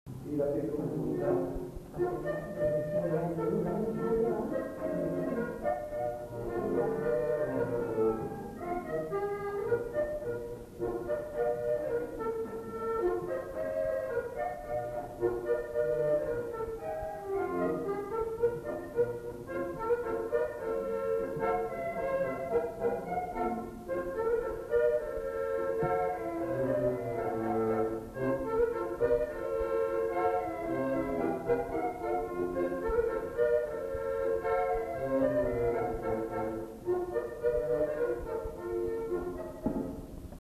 enquêtes sonores
Polka